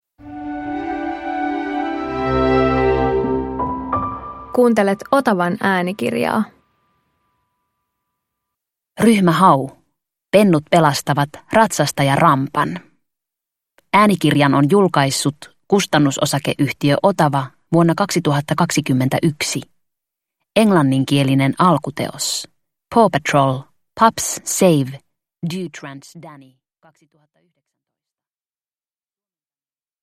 Ryhmä Hau - Pennut pelastavat Ratsastaja-Rampan – Ljudbok – Laddas ner